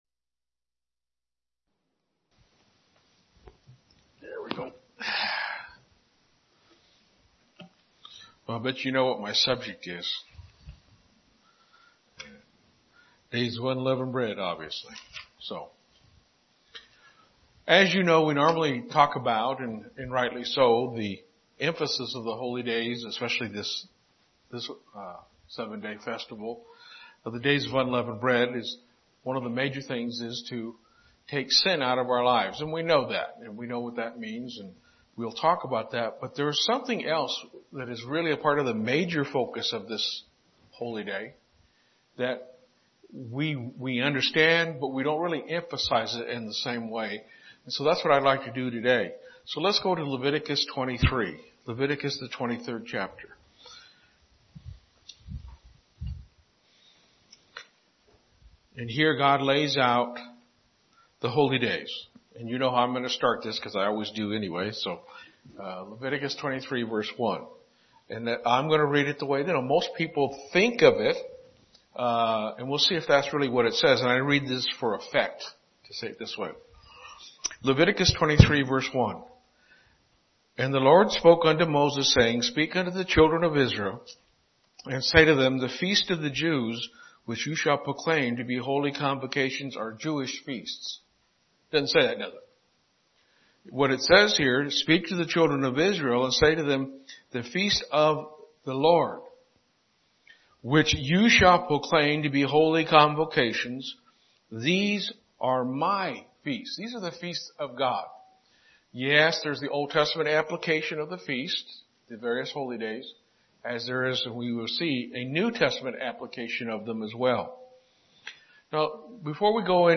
Holy Day Services Studying the bible?